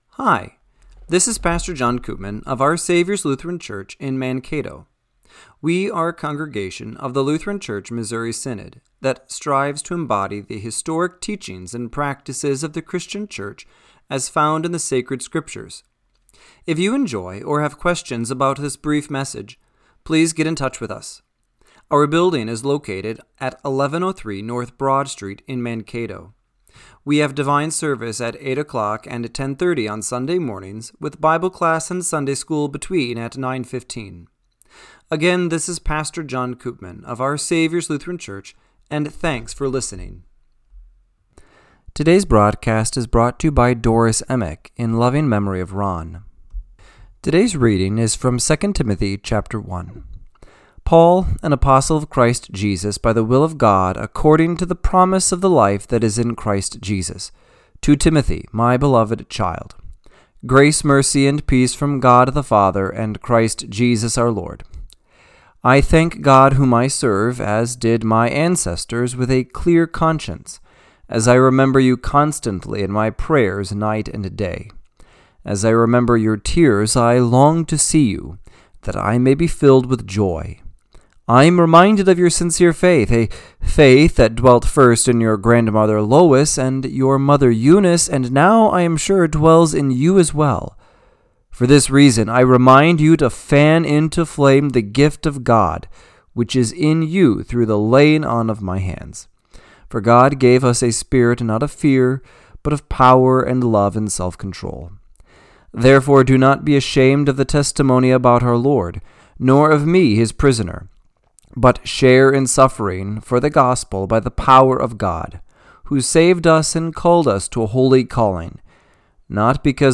Radio-Matins-10-5-25.mp3